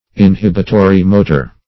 Search Result for " inhibitory-motor" : The Collaborative International Dictionary of English v.0.48: Inhibitory-motor \In*hib"i*to*ry-mo"tor\, a. (Physiol.) A term applied to certain nerve centers which govern or restrain subsidiary centers, from which motor impressions issue.